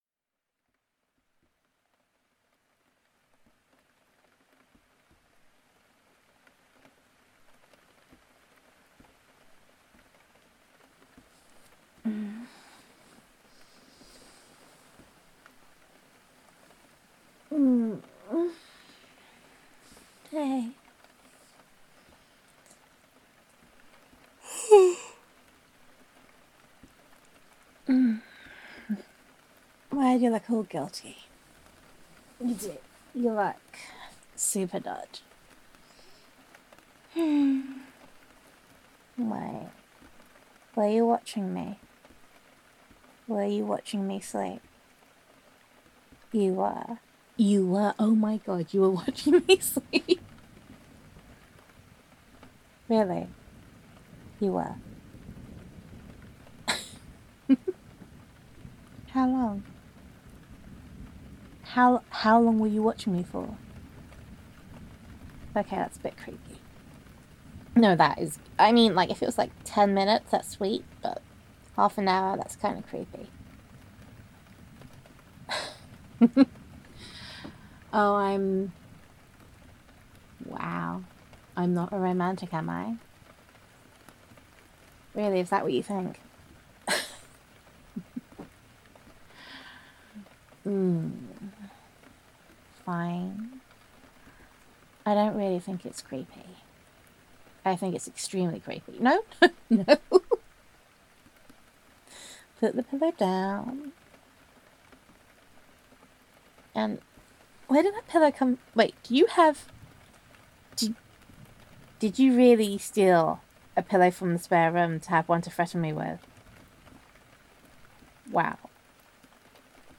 [F4A] I Almost Said It